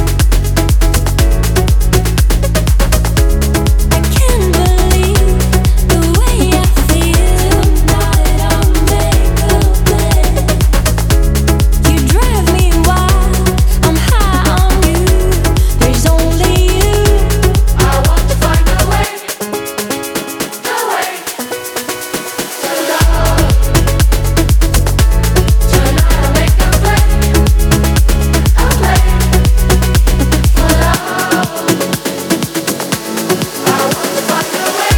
Afro House African